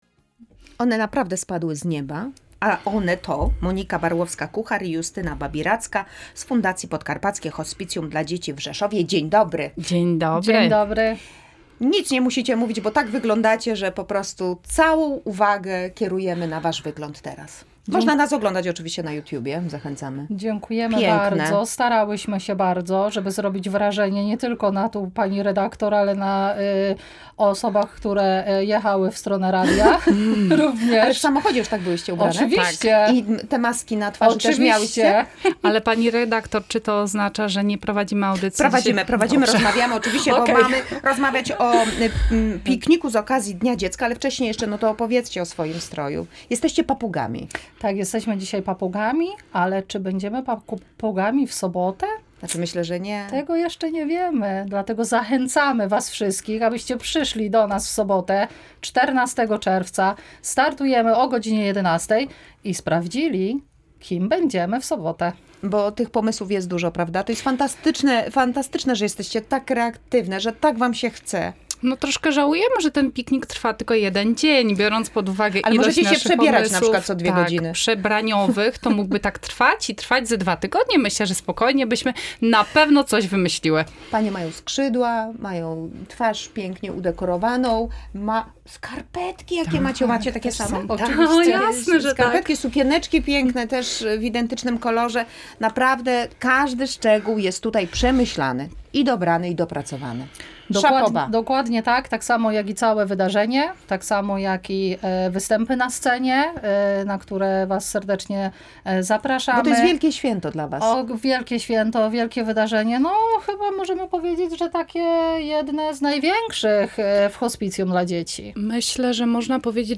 W „Tu i Teraz” odwiedziły nas organizatorki Pikniku z okazji Dnia Dziecka w Fundacji Podkarpackie Hospicjum dla Dzieci z siedzibą w Rzeszowie. Wydarzenie już w najbliższą sobotę.